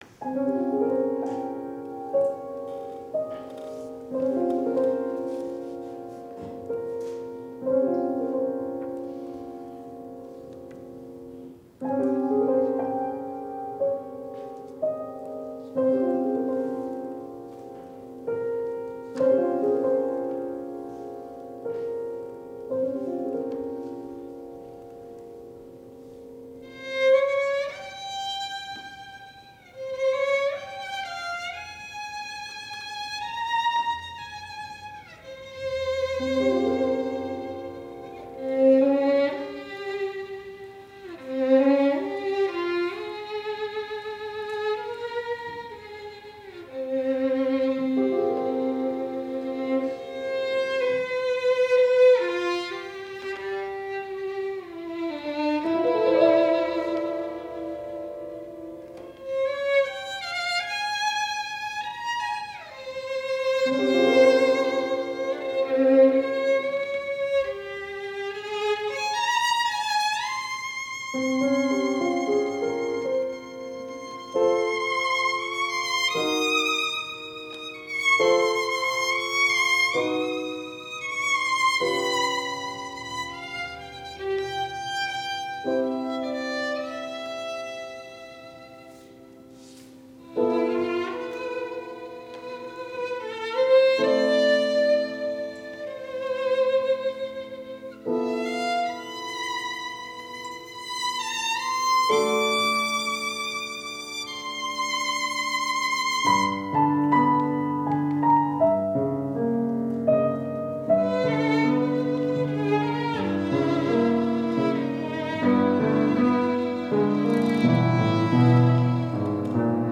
Instrumentation: Violin, Piano